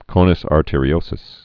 (kōnəs är-tîrē-ōsəs)